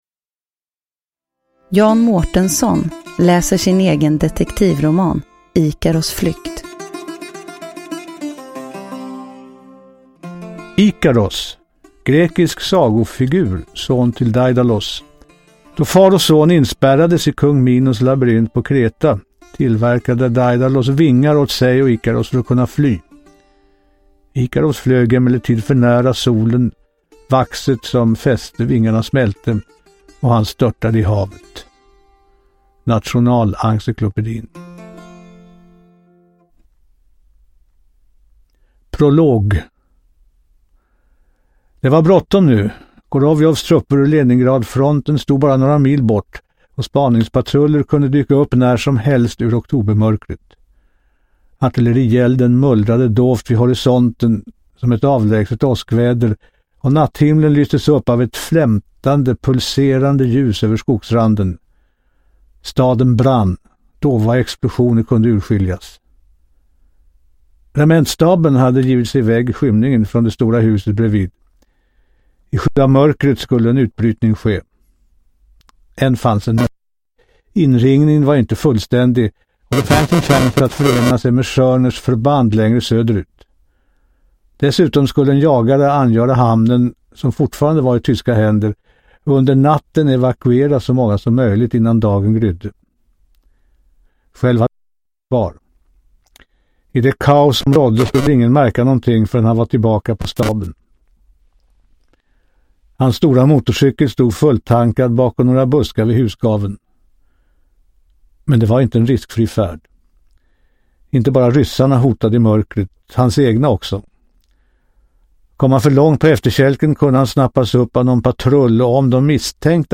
Uppläsare: Jan Mårtenson
Ljudbok